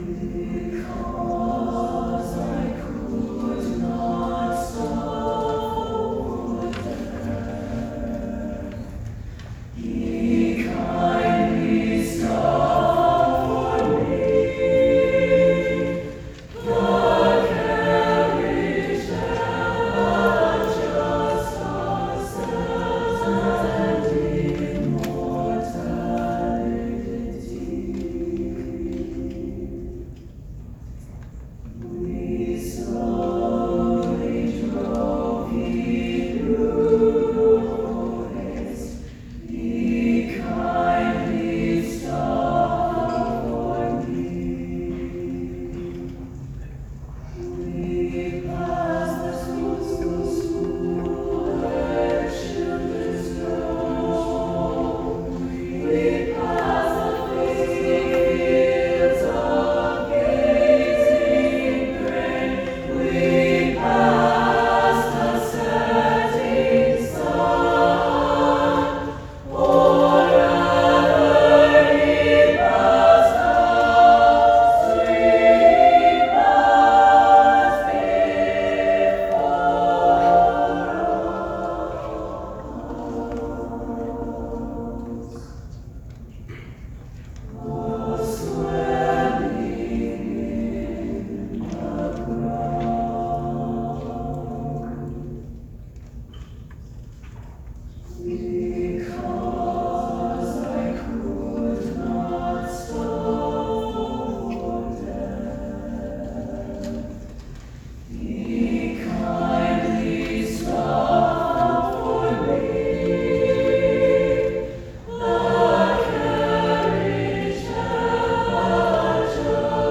SATB Chorus with Divisi and piano